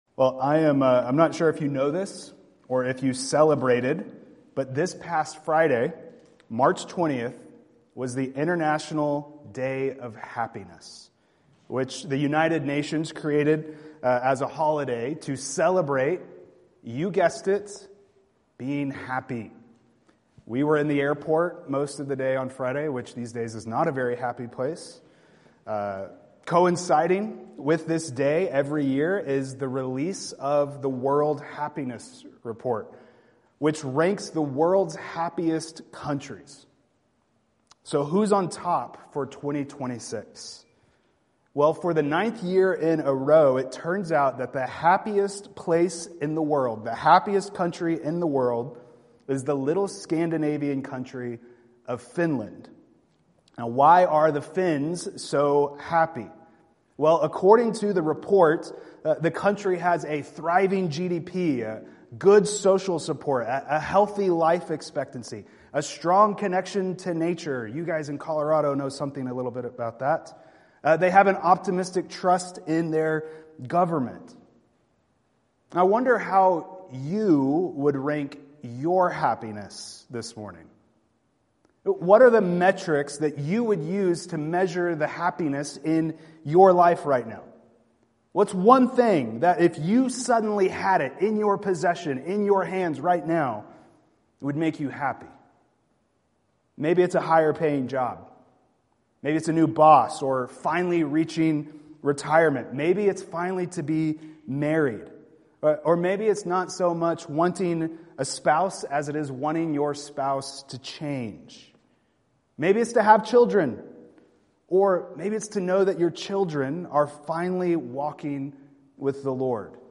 Sermons
Service: Sunday Morning